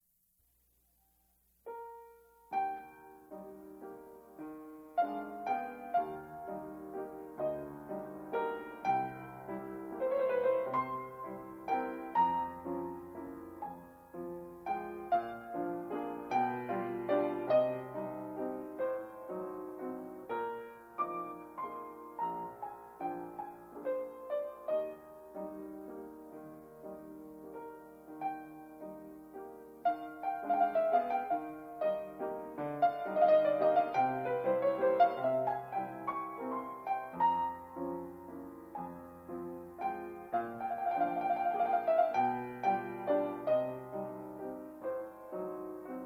・拍子記号は８分の１２拍子！８分の６拍子が２セットで構成、ゆったりでエレガントな１小節運び
・演奏の速さは、♪=６０くらいから１２０くらいまでふわふわ。テンポの揺れが存分に感じられる
ハンモックに揺られるような、暖かで心地よい揺らぎがありますよね。